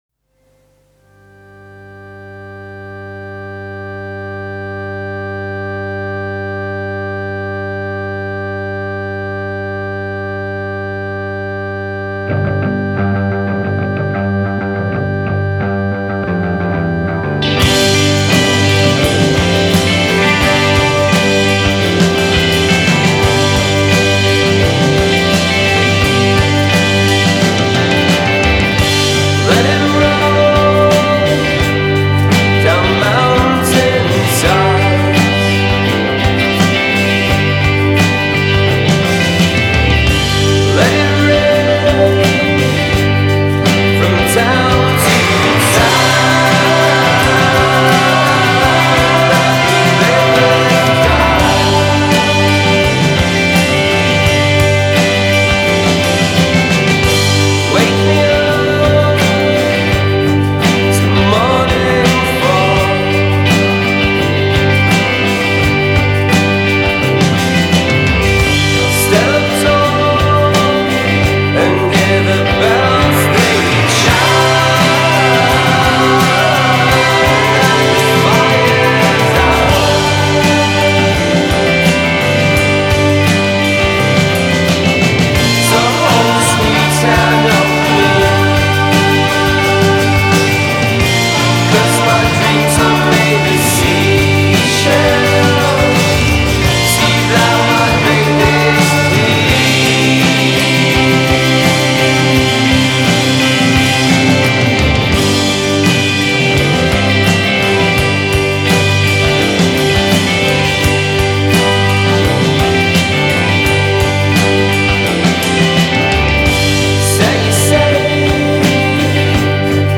Genre: Indie Pop-Rock / Psychedelic Rock